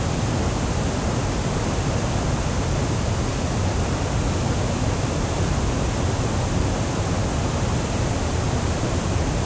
waterfall.ogg